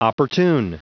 Prononciation du mot opportune en anglais (fichier audio)
Prononciation du mot : opportune